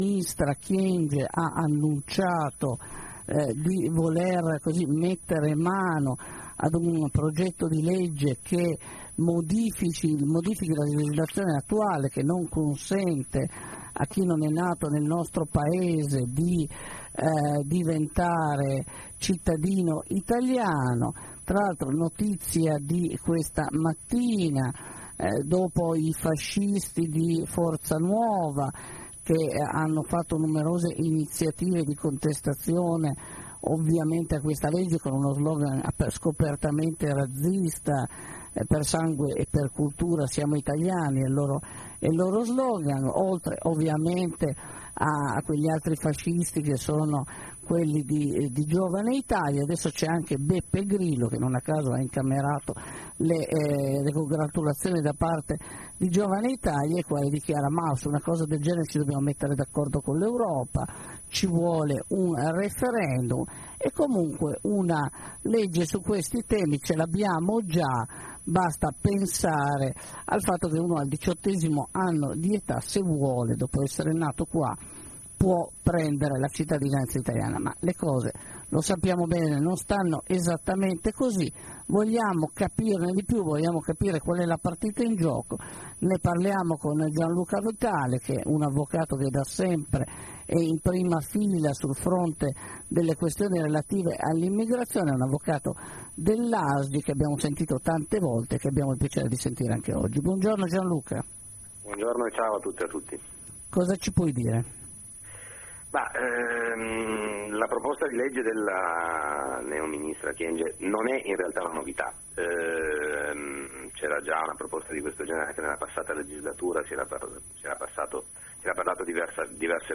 Per capirne di più Anarres ne ha parlato